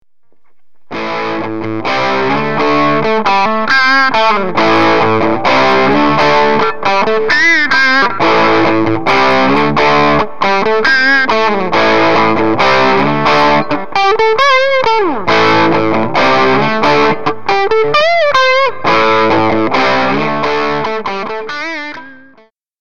Guitare : Vigier excalibur
Réverbe SE70 devant le G1
distoG1.mp3